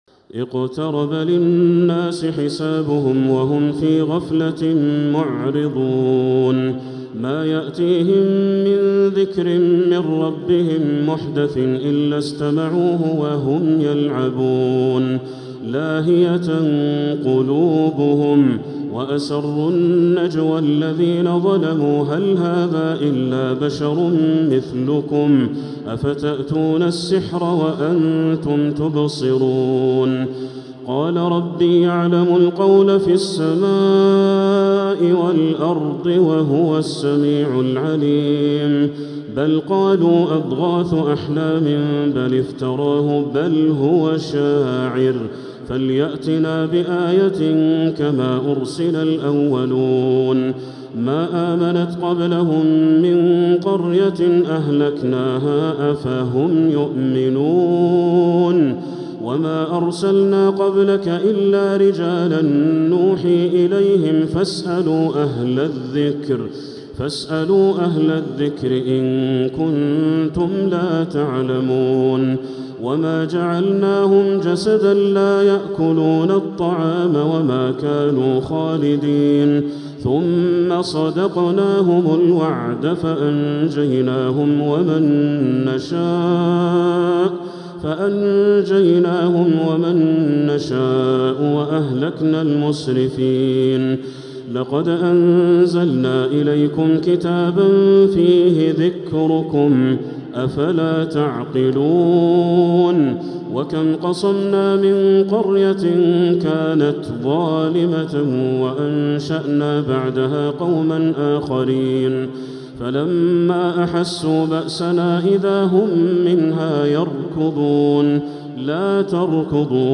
من المسجد الحرام